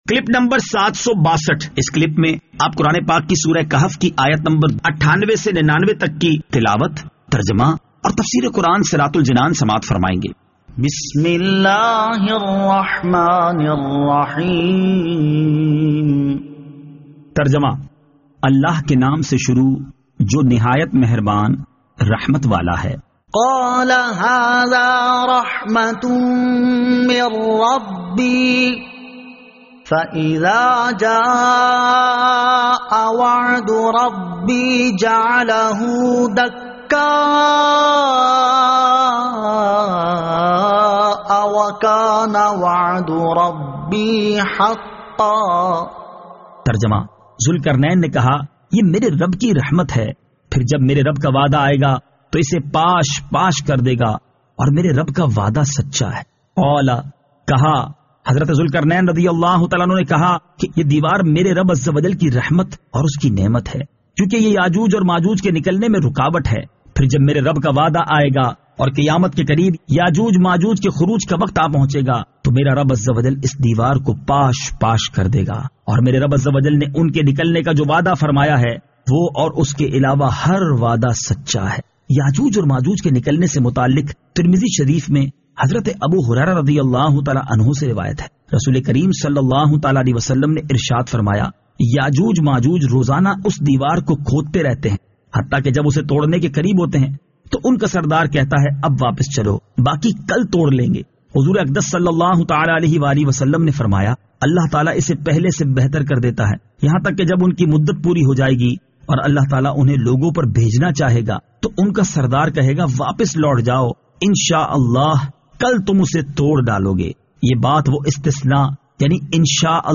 Surah Al-Kahf Ayat 98 To 99 Tilawat , Tarjama , Tafseer